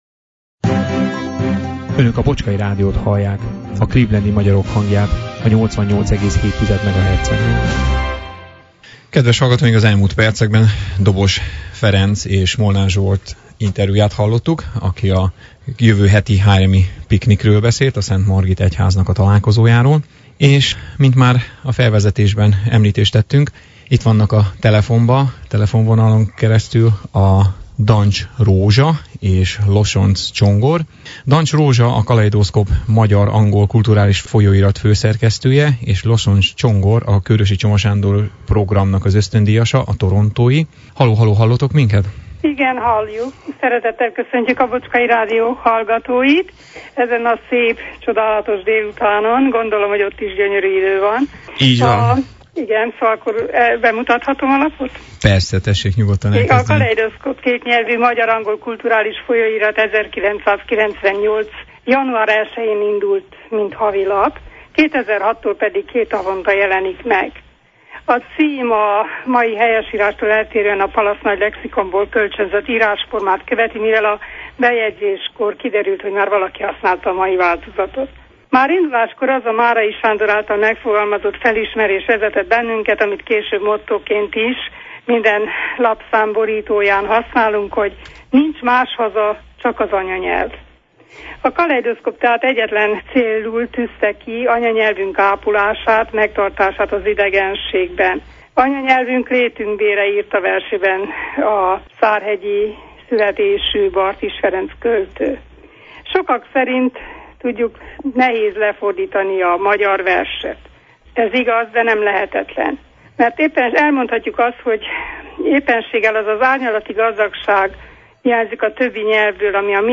Telefoninterjúban